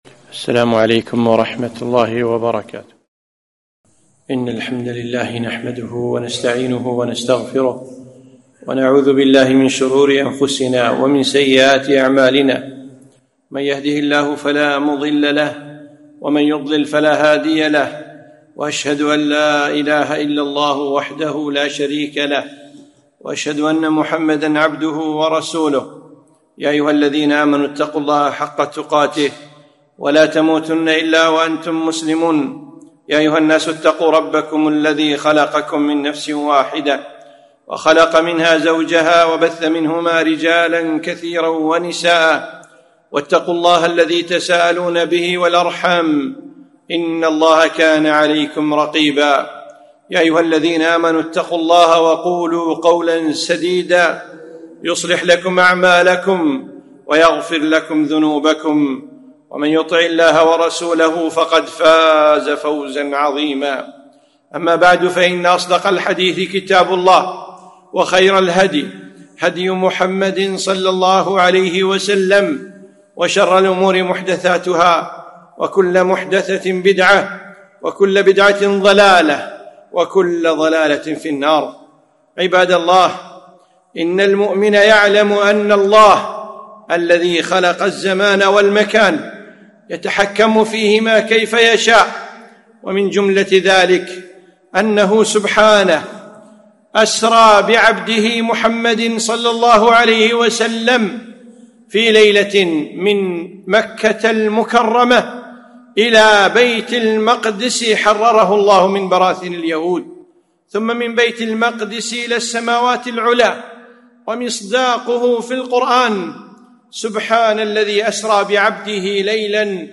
خطبة - الأسراء